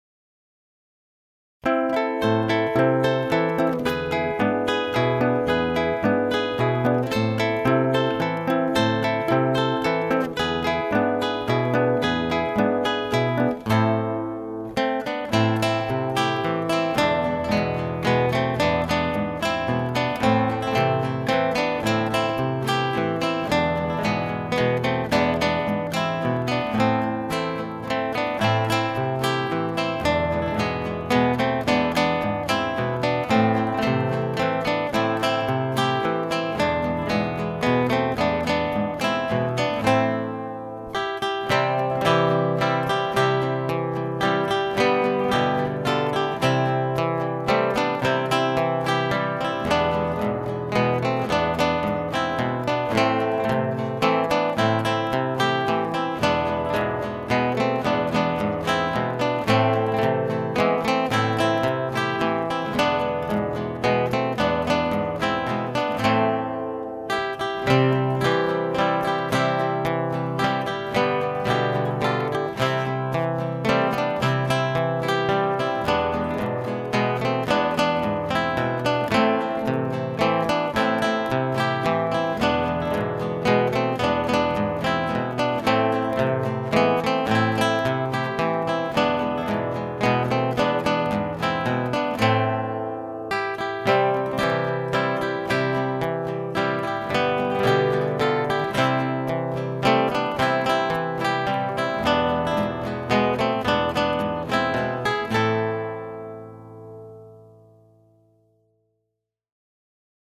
4 Gitarren